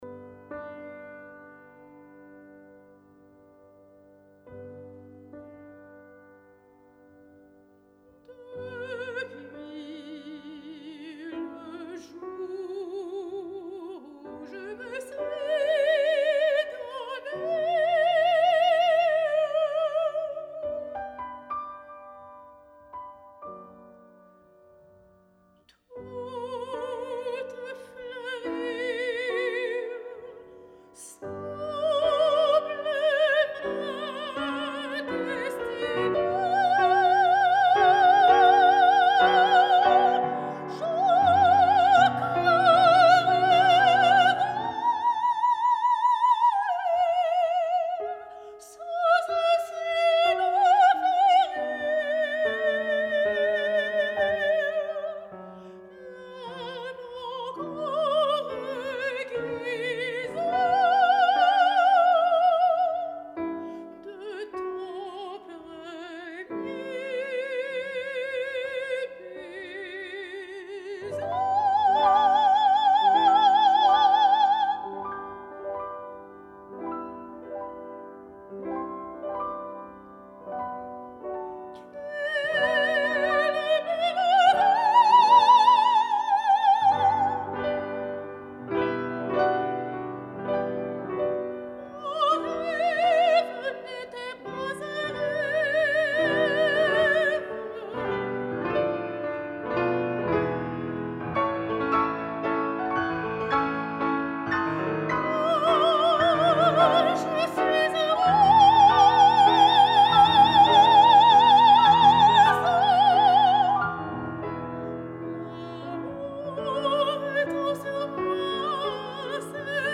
soprano
Paroisse Saint-Angèle, Saint-Léonard, Qc
Piano